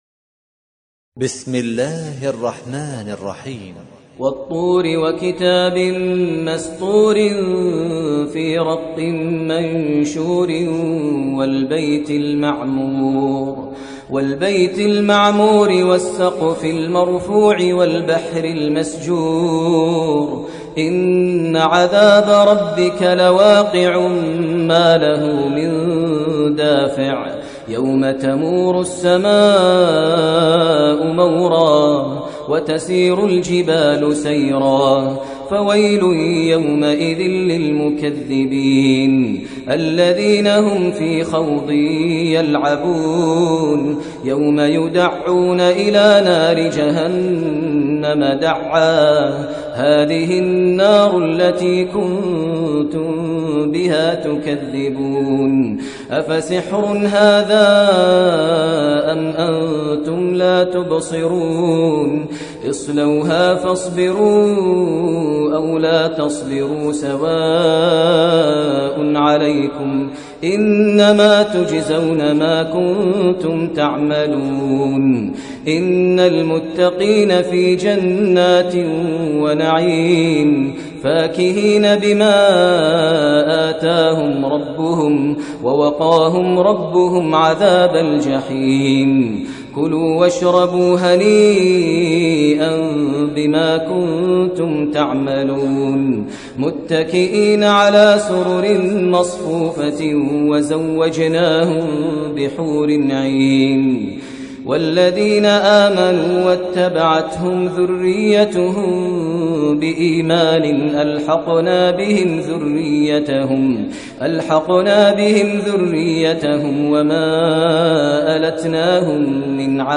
ترتیل سوره طور با صدای ماهر المعیقلی
052-Maher-Al-Muaiqly-Surah-At-Tur.mp3